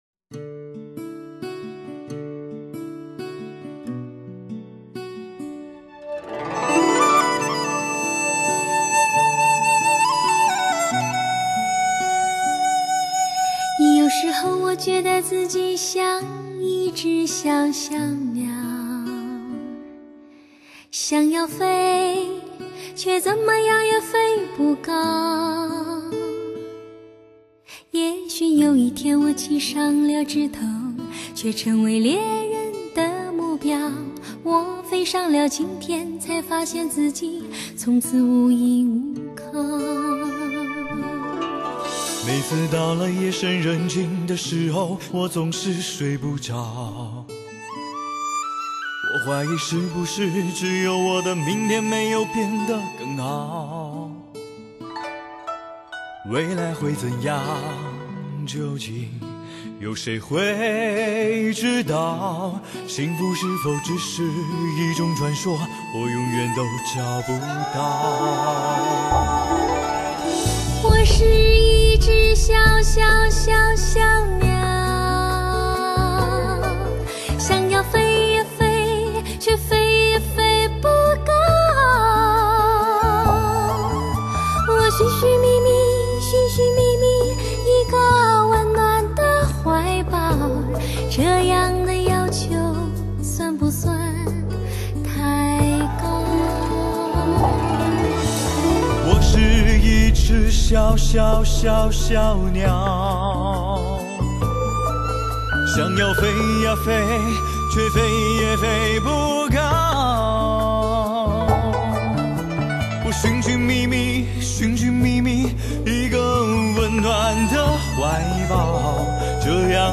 温情四溢浪漫天碟 带你回到真情的梦幻世界
人声演绎自然，录音精致，醉人的情怀令人感动。